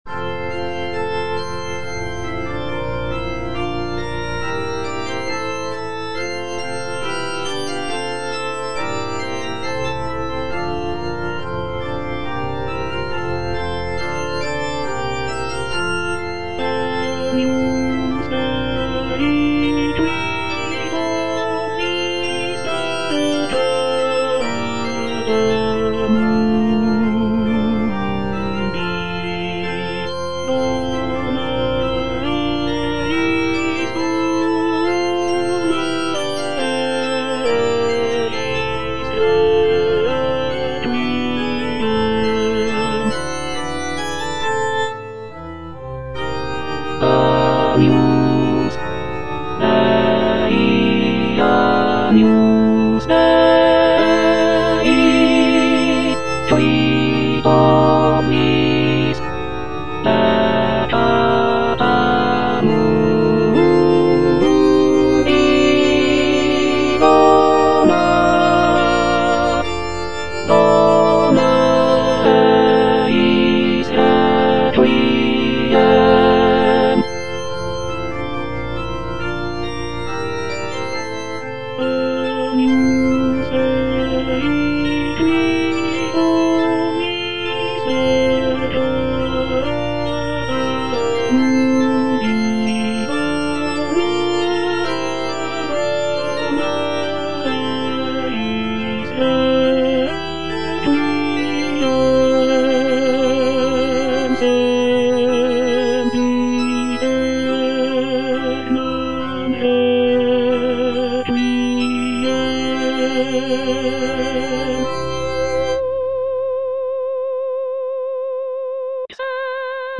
G. FAURÉ - REQUIEM OP.48 (VERSION WITH A SMALLER ORCHESTRA) Agnus Dei - Alto (Emphasised voice and other voices) Ads stop:
This version features a reduced orchestra with only a few instrumental sections, giving the work a more chamber-like quality.